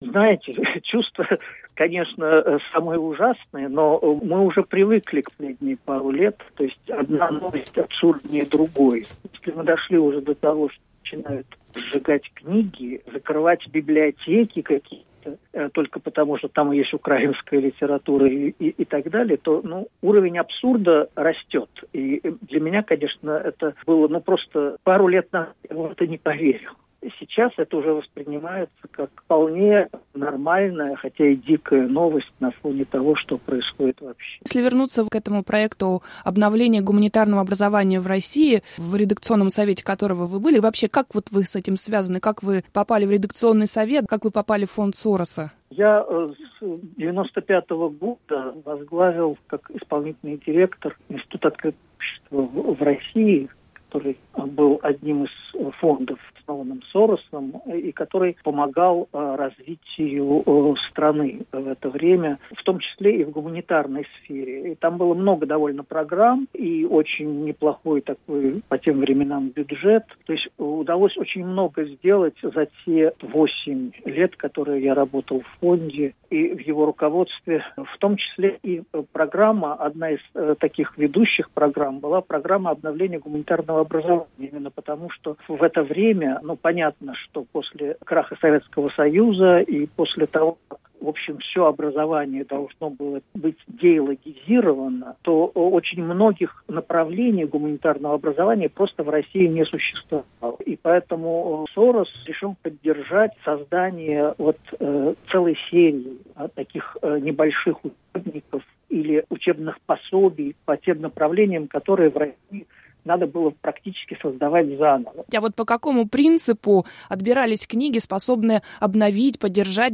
В интервью Радио Свобода он рассказал, для чего создавалась эта программа, и как воспринимается новость о том, что изданные при поддержке Фонда Сороса учебники начали сжигать: